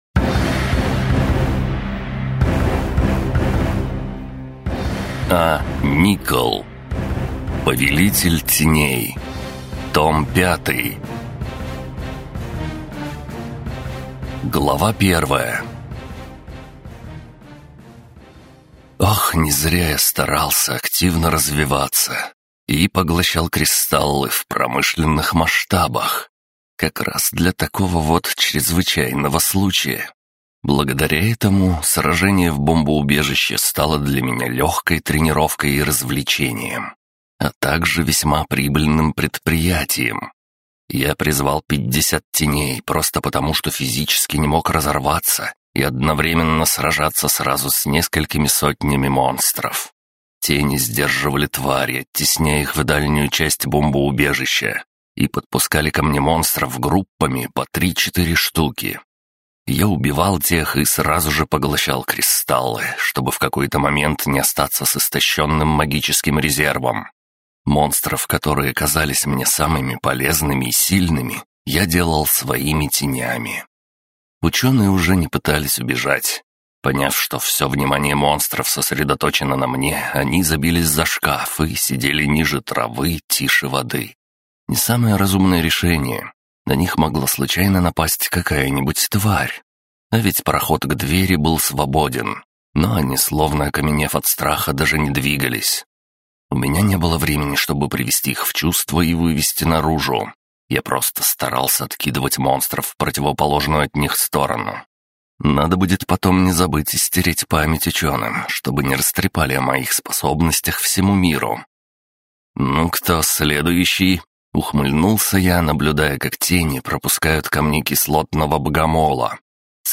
Звуковое оформление и исполнение текста создают уникальное впечатление, погружая слушателя в мир магии и приключений. Голос исполнителя передает эмоции героев, делая каждый момент захватывающим.